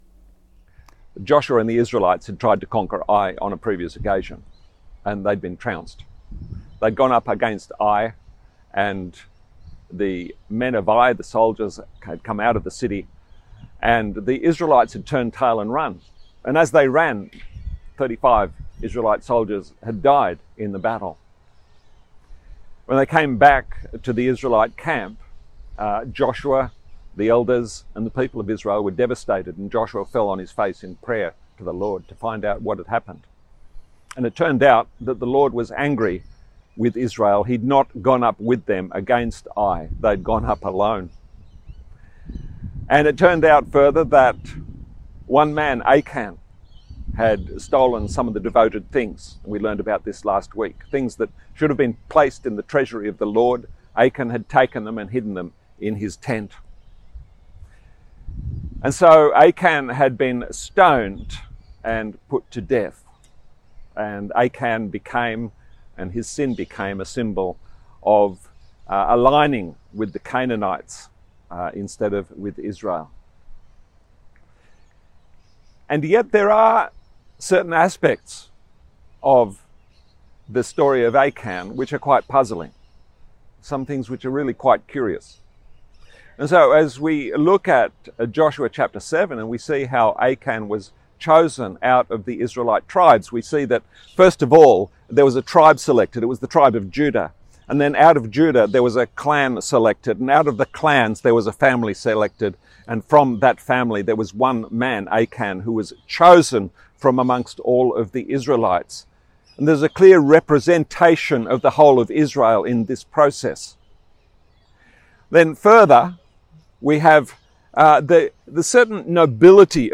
Sermons | St Johns Anglican Cathedral Parramatta